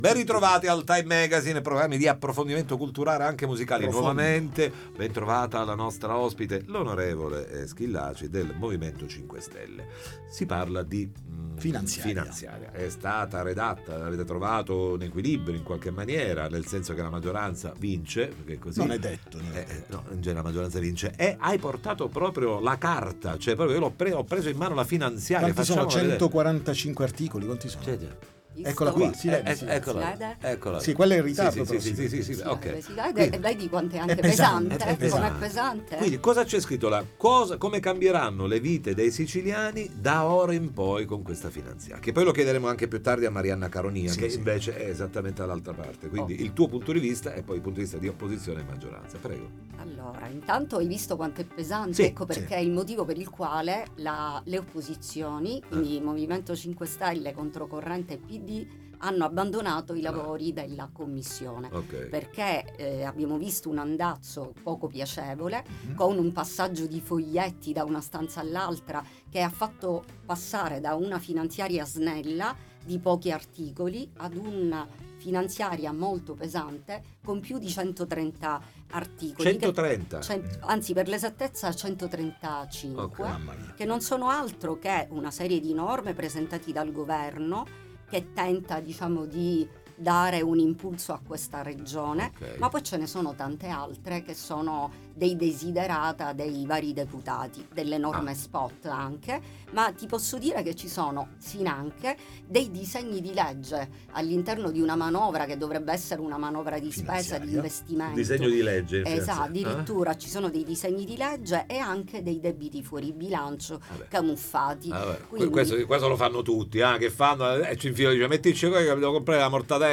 Roberta Schillaci al Time Magazine parla di finanziaria Interviste Time Magazine 11/12/2025 12:00:00 AM / Time Magazine Condividi: L’On.